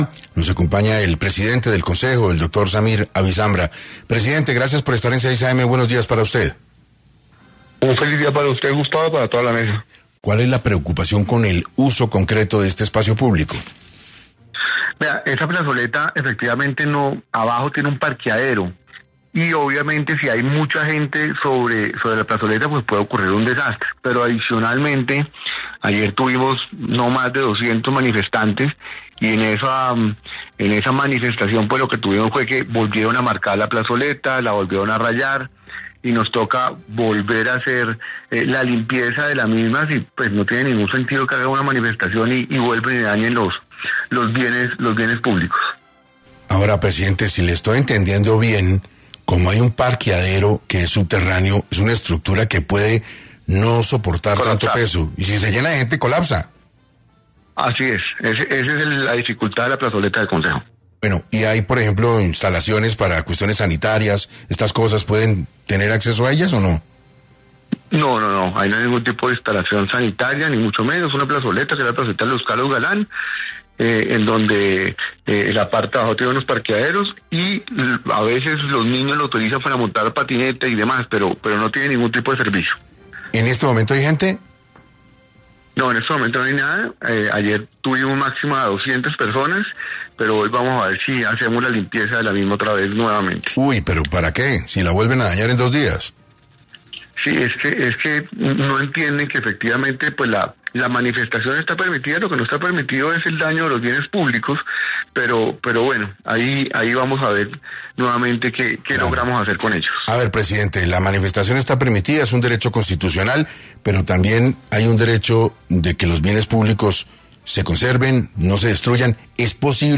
En entrevista para 6AM, el presidente del Concejo, Samir Abisambra, explicó cómo se desarrolla la situación e hizo un llamado a la ciudadanía y los grupos indígenas.